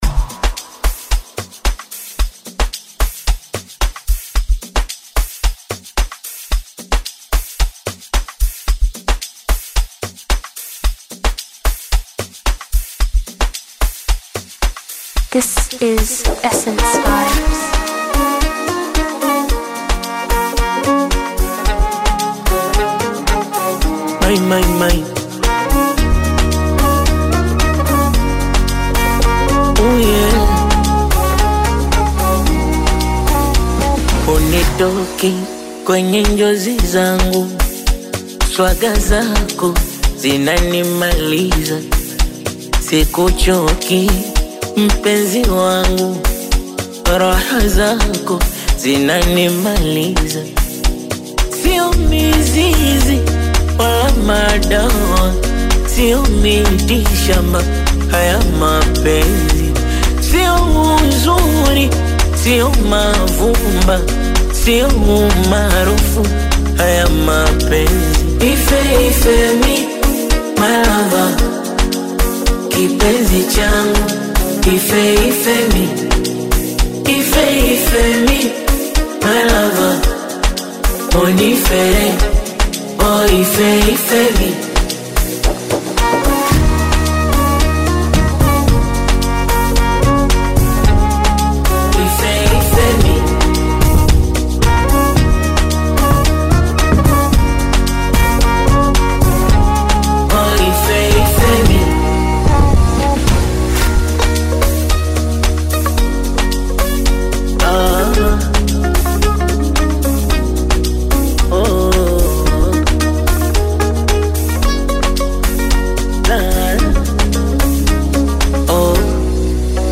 love song
smooth melodies
a soulful vocal performance
Afro-pop